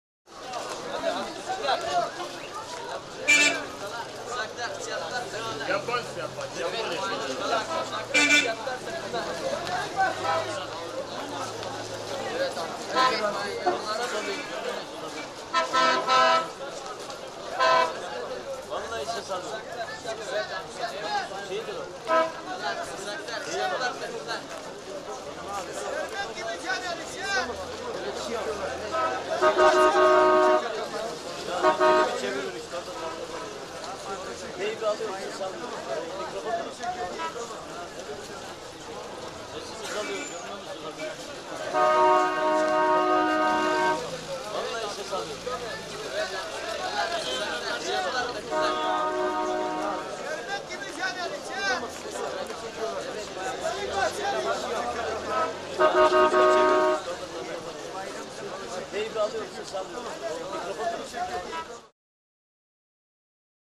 Market; Arabic; Arabic Market Atmosphere. Light Chatter, Some Traders Shouts And Vehicle Horn Toots As Per Trying To Get Through Crowd.